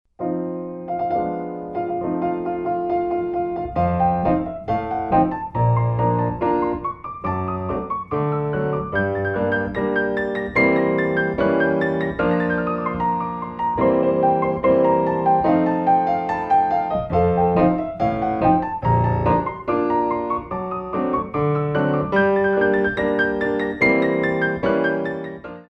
Coda ou Fouettés Manége